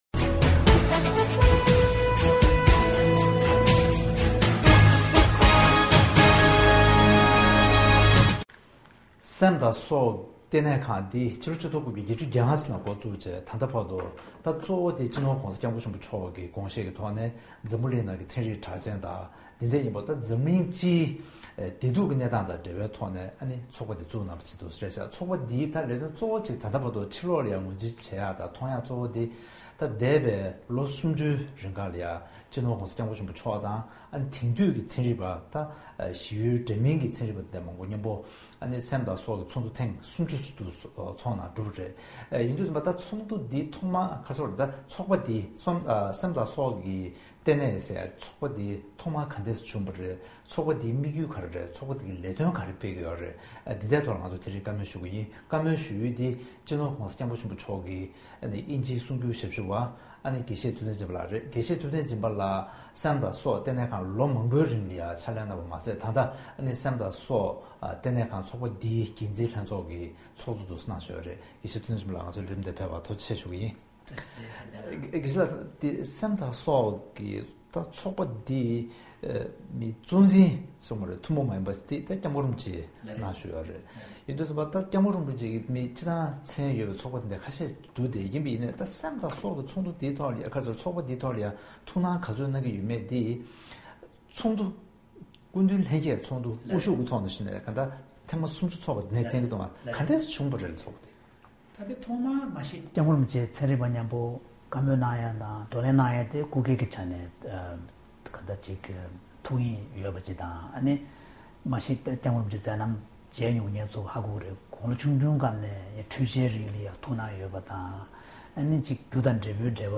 ཐེངས་འདིའི་གནད་དོན་གླེང་མོལ་གྱི་ལེ་ཚན་ནང་།